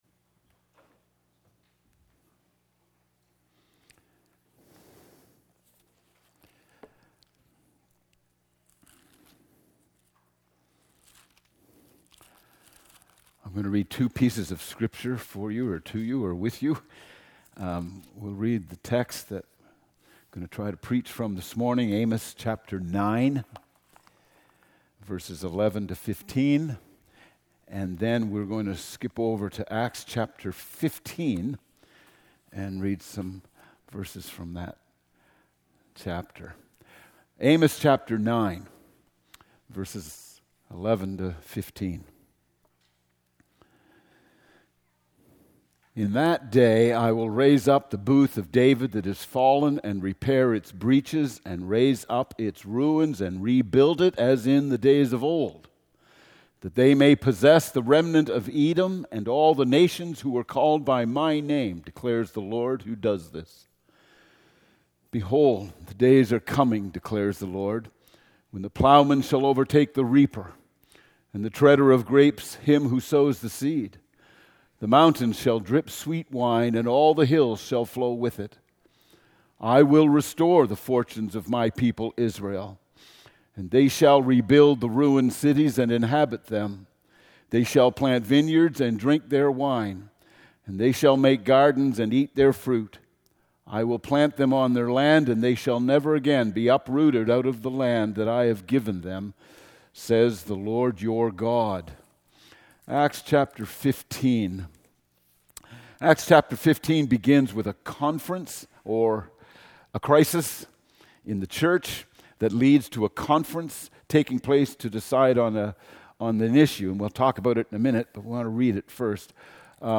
Sermons | Faith Baptist Church